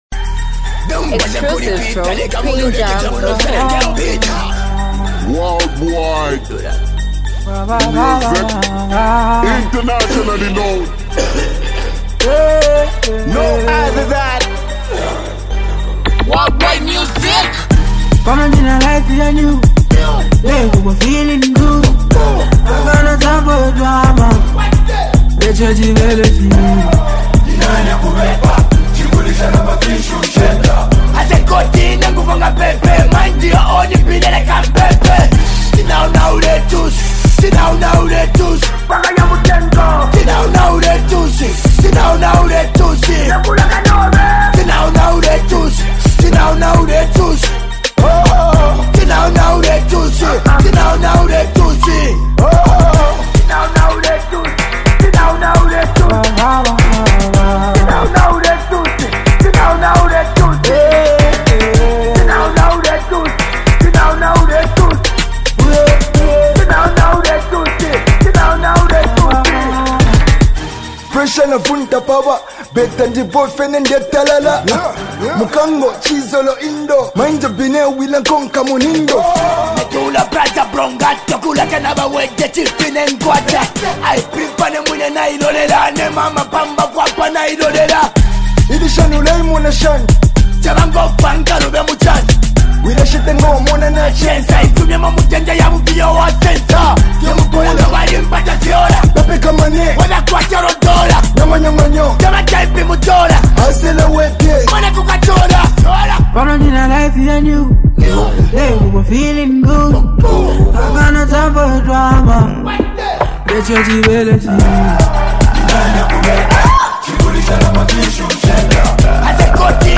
hype, street vibes, and a powerful hook with unique rap flow